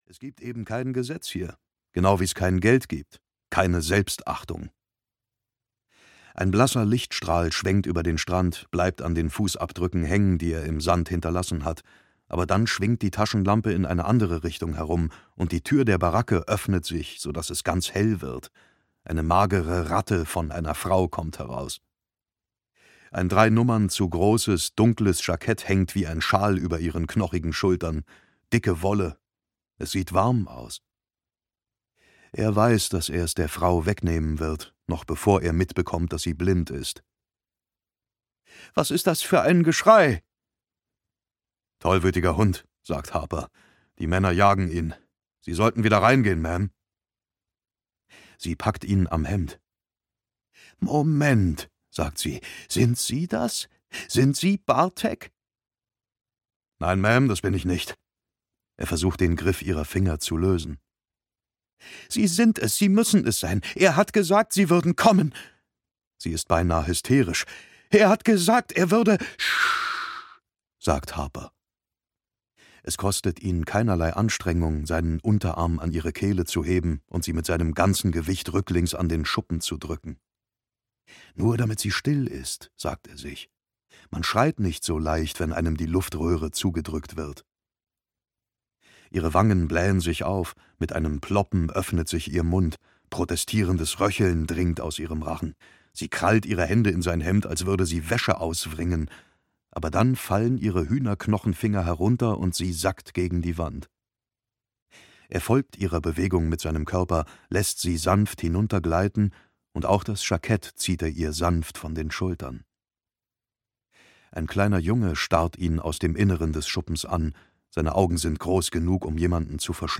Shining Girls - Lauren Beukes - Hörbuch